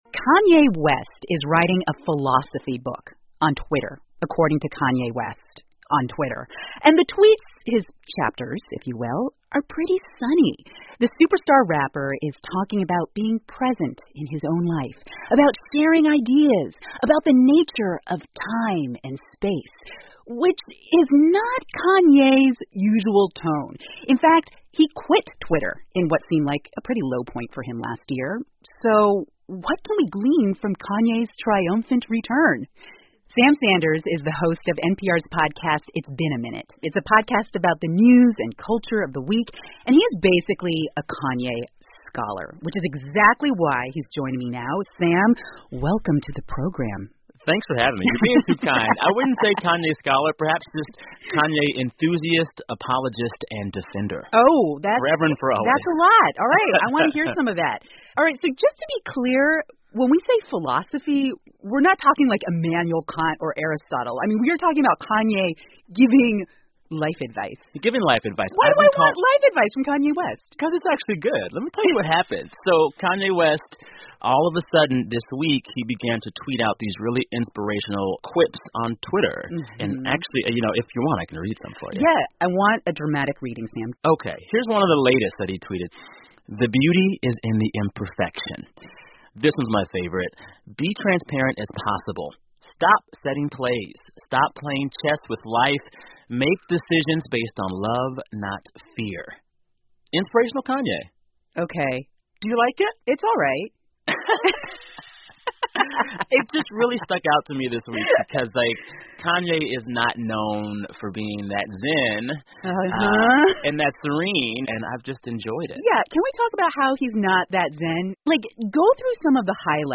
美国国家公共电台 NPR A Conversation About Kanye West, Twitter Philosopher 听力文件下载—在线英语听力室